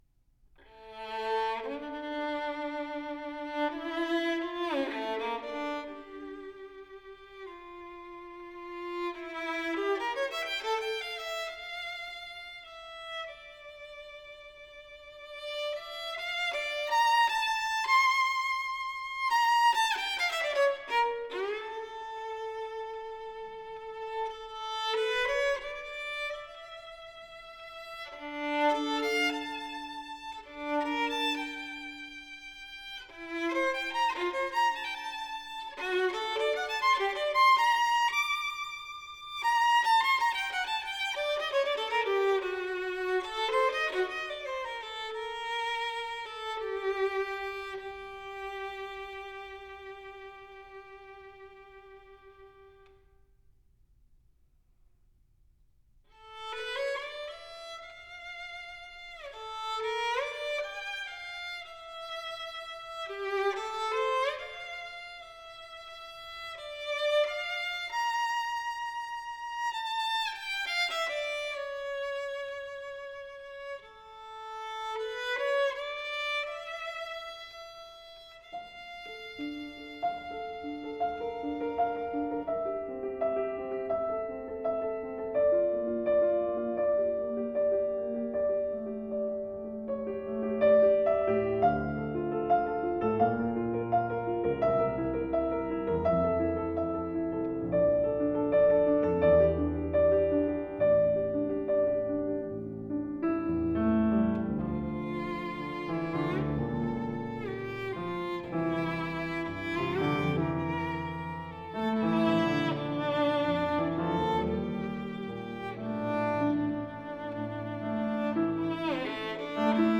戏剧性的音乐张力，炫技的演出，扣人心弦！